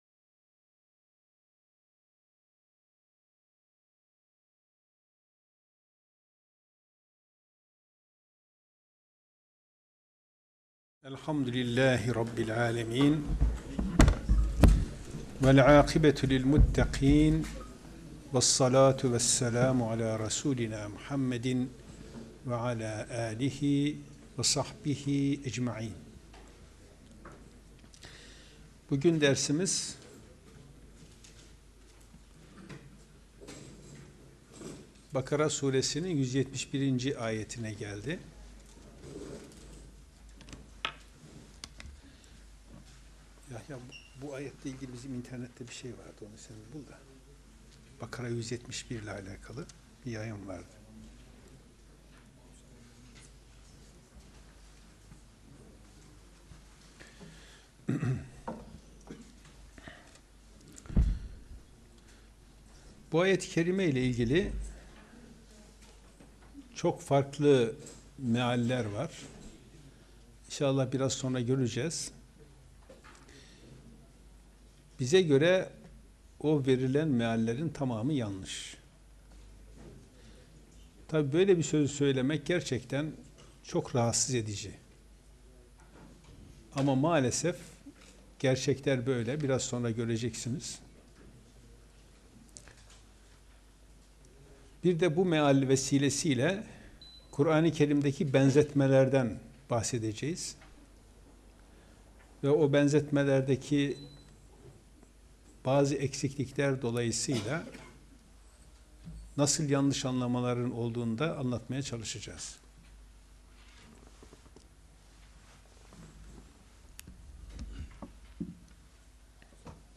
Gösterim: 4.796 görüntülenme Kur'an Sohbetleri Etiketleri: bakara 171. ayet > göz > kalbin mühürlenmesi ne demektir > kalp > kulakların mühürlenmesi > kuran sohbetleri Elhamdülillahi rabbil alemin.